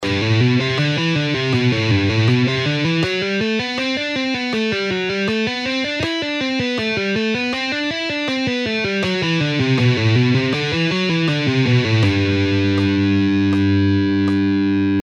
Legato Exercise in G Major Scale:
Half Speed:
3.-Legato-Exercise-In-G-Major-Scale-Half-Speed.mp3